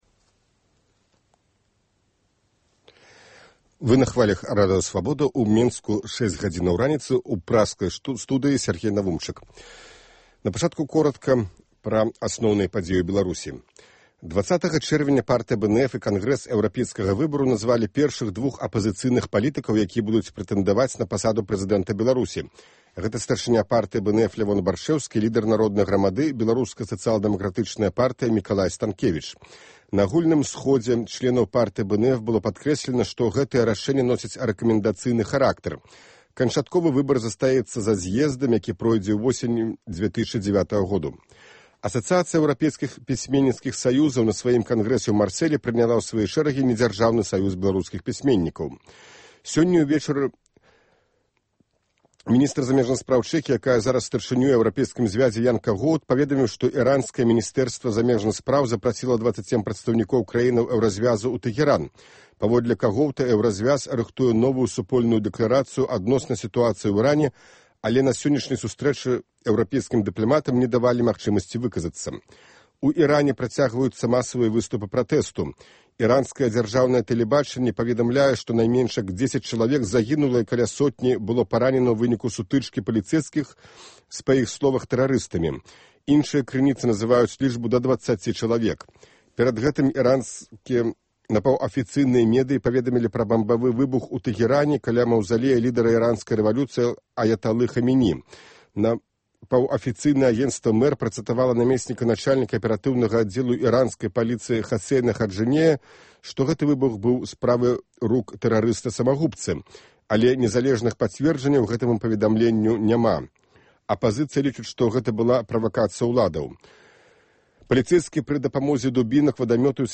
Асноўныя падзеі, бліц-аналіз, досьледы і конкурсы, жывыя гутаркі, камэнтары слухачоў, прагноз надвор'я, "Барды Свабоды".